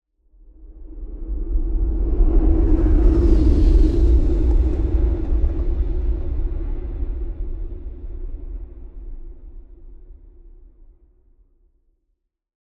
SCIShip-Passage-vaisseau-spatial-1-ID-1971-LS.wav